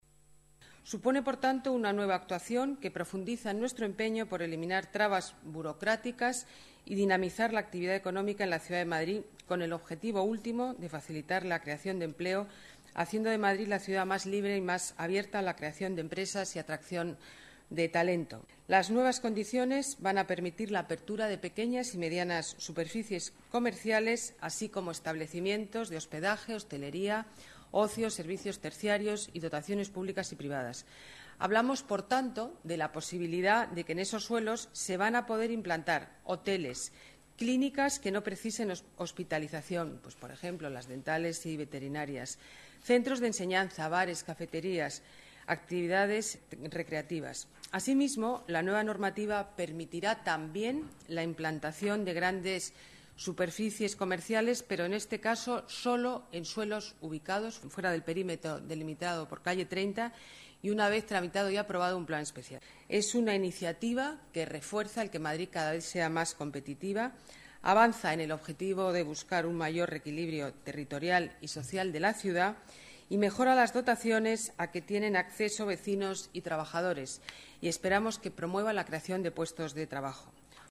Nueva ventana:Declaraciones de la alcaldesa